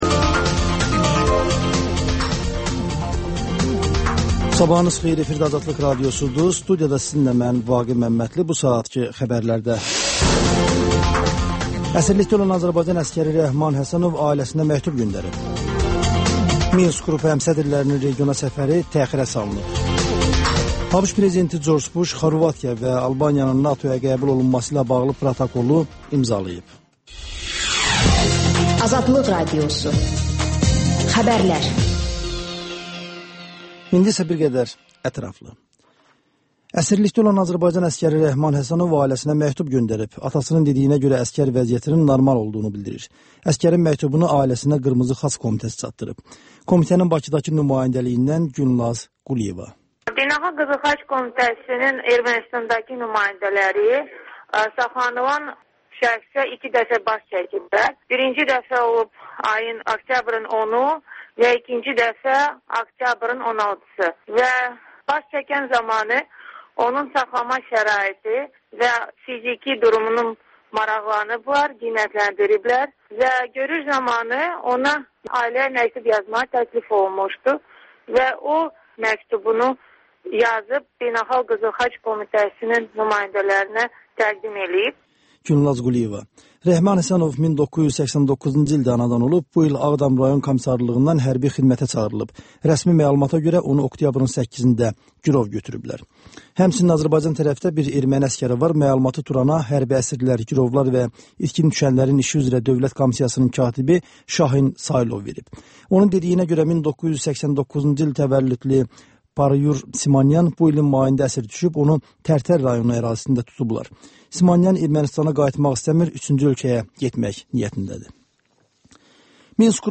Xəbərlər, müsahibələr, hadisələrin müzakirəsi, təhlillər, sonda HƏMYERLİ rubrikası: Xaricdə yaşayan azərbaycanlılar haqda veriliş